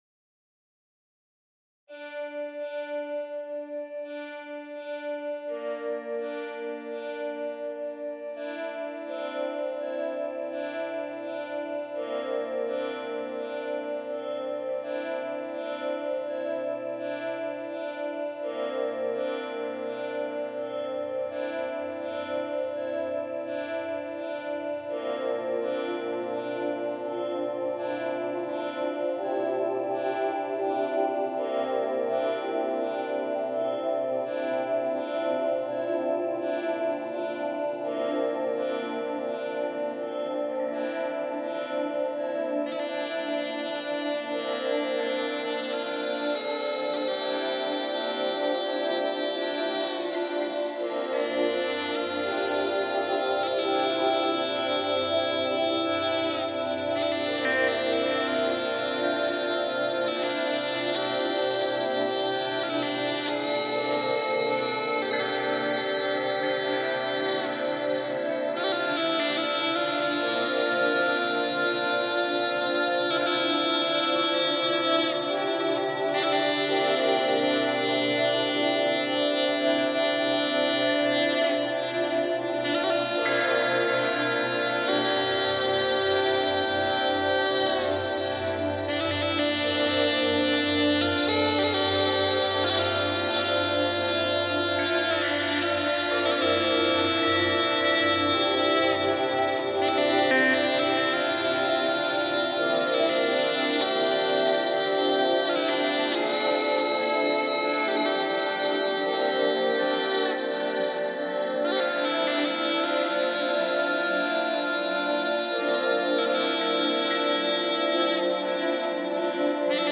Vánoční koledy mytologické - západní Dzūkija
Místo: Babriškės